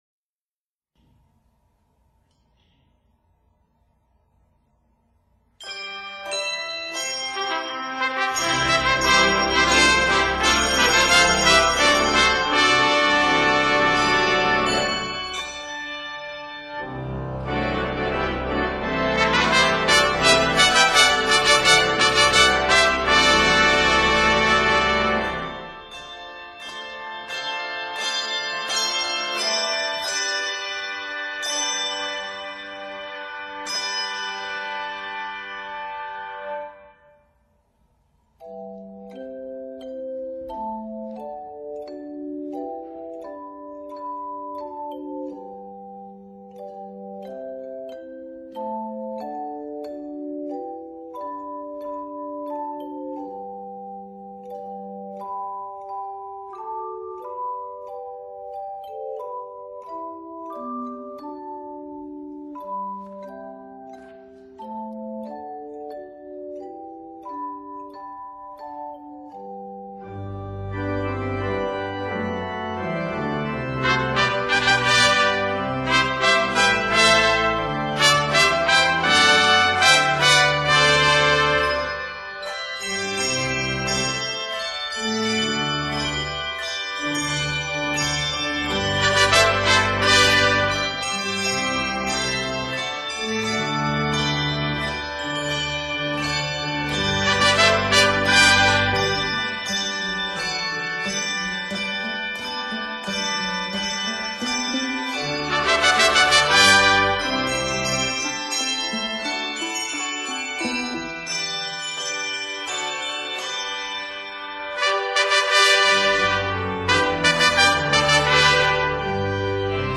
The traditional hymn tune